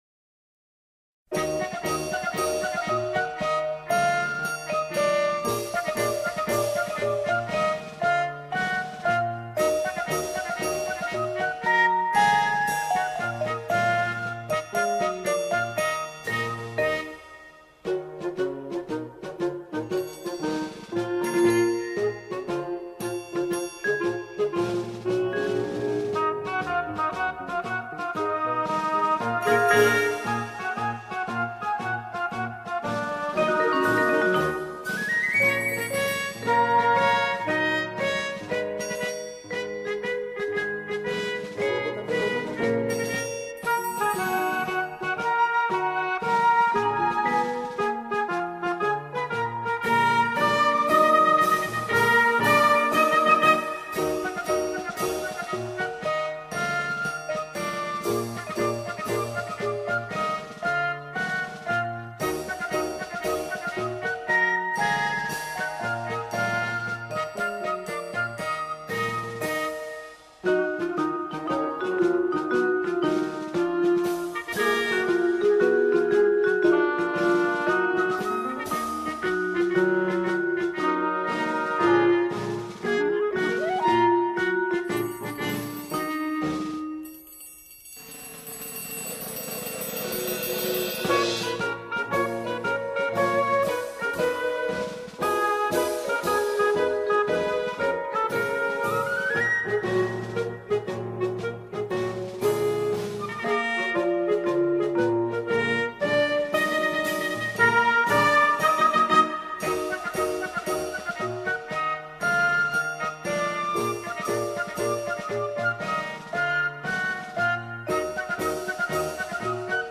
سرودهای بسیج
بی‌کلام